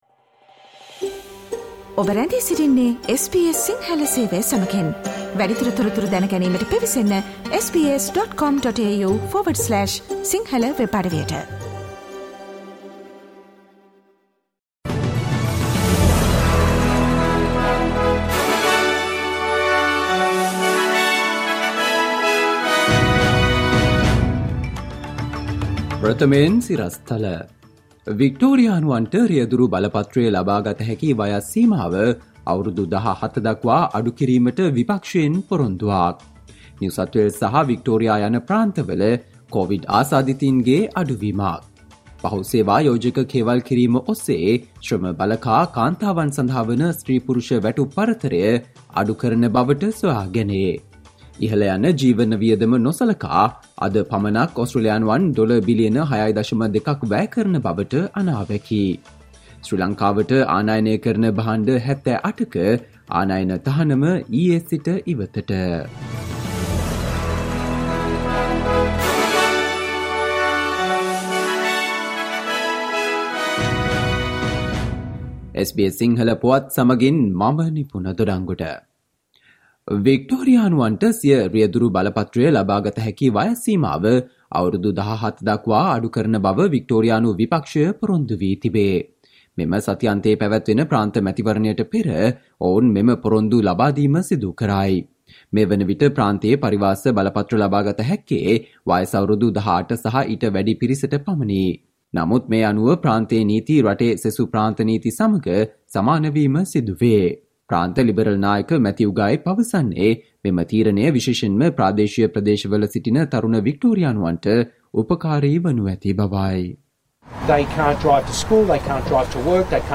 Listen to the SBS Sinhala Radio news bulletin on Friday, 25 November 2022